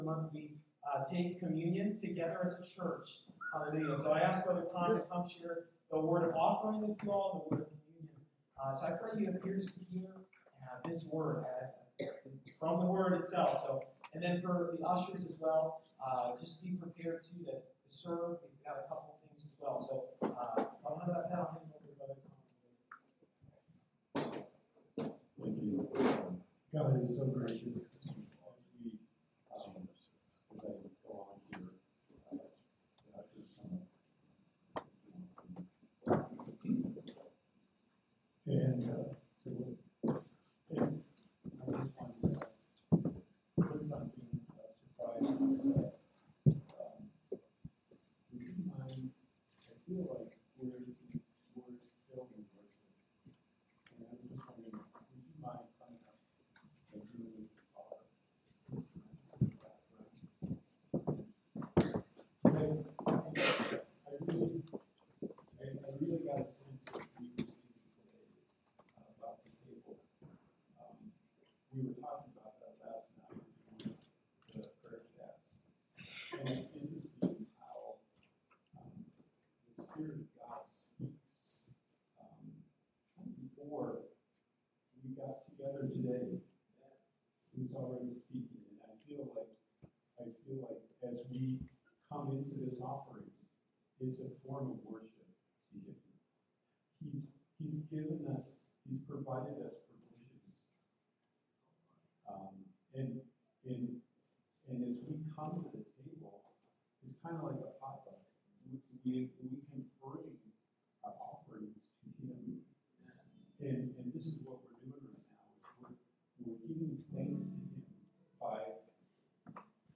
Series: A Wave of Grace Service Type: Sunday Morning